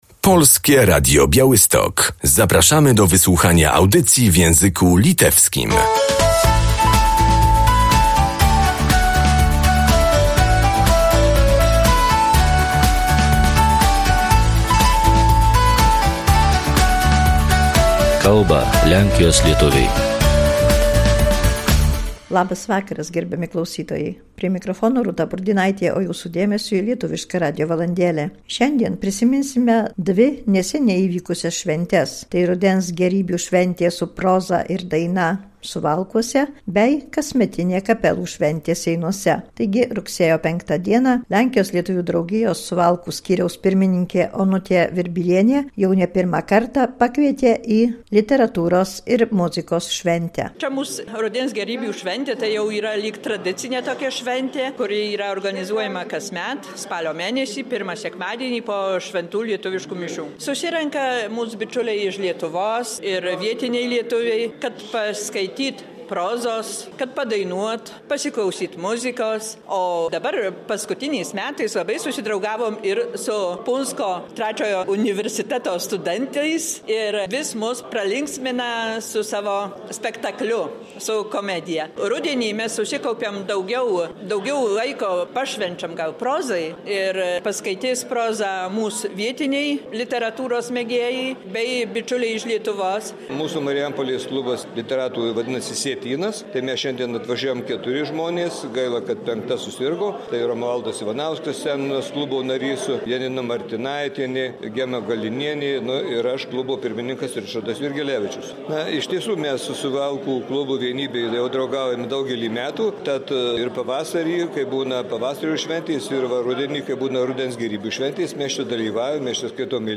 Relacje ubarwiają fragmenty występów